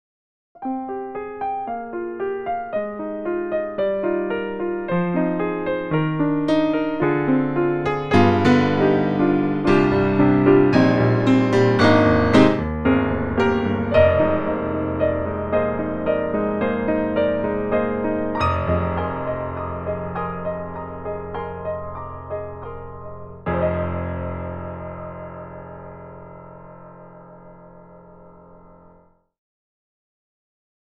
特集：徹底比較！DTM音源ピアノ音色聴き比べ - S-studio2
ConcertGrand(EmulatorX)
E-MU_EmulatorX_ConcertGrand.mp3